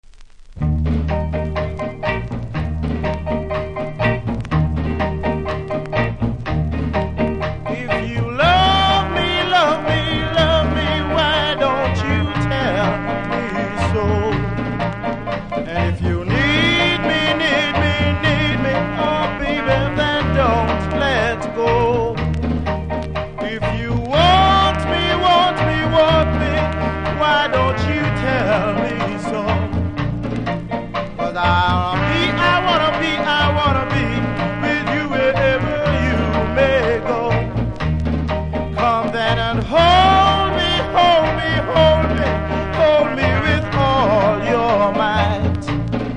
キズもノイズも少なめなので試聴で確認下さい。